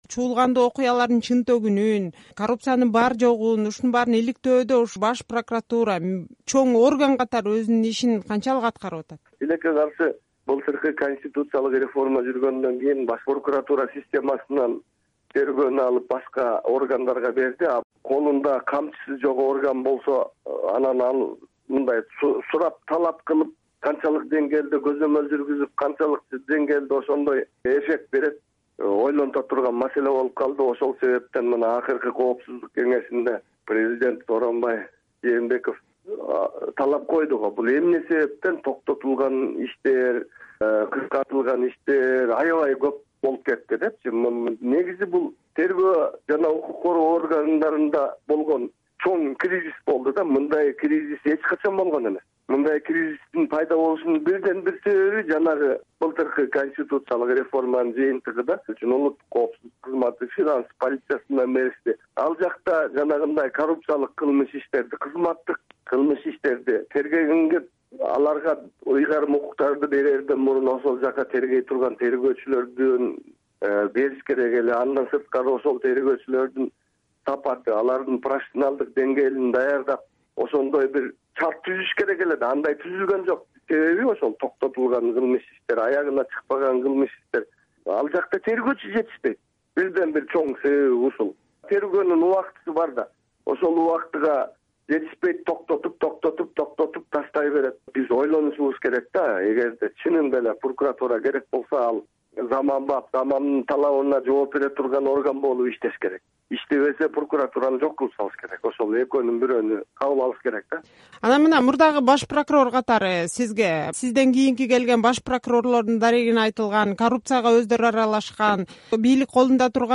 Мурдагы баш прокурорБайтемир Ибраев "Азаттык" радиосуна курган маегинде ушул тапта тергөө жана укук коргоо органдарында чоң кризис жаралганына, баш прокурорду саясий инстутуттар “куралга” айлантып жатканына жана башкы көзөмөл мекемесинин жетекчисинин ролуна токтолду.